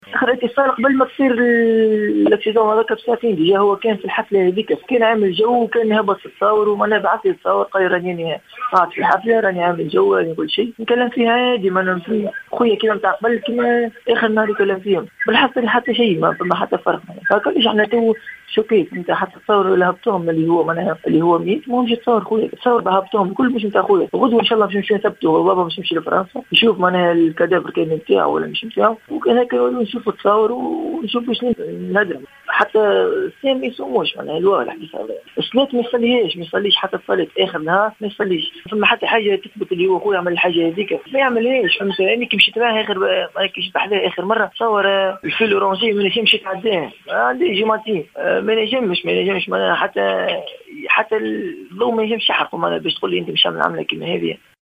تصريح حصري للجوهرة أف أم